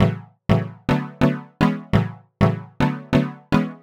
cch_synth_rompler_125_Em.wav